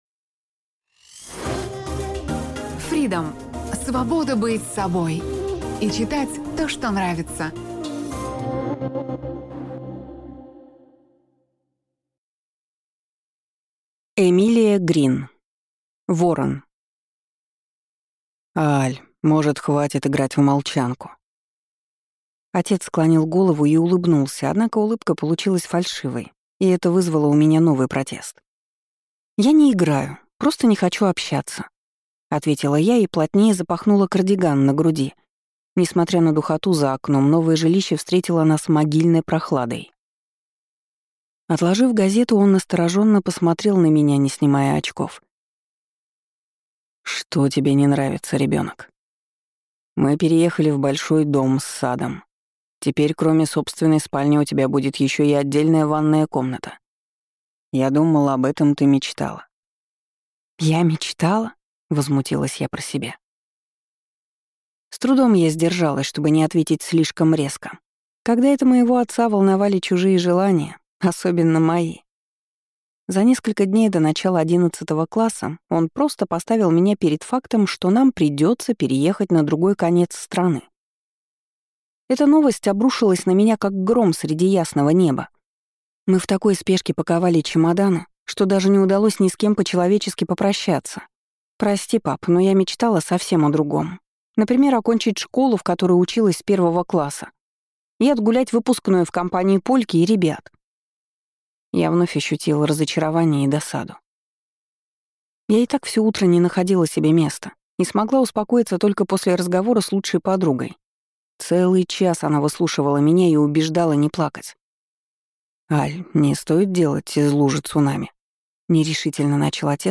Аудиокнига Ворон | Библиотека аудиокниг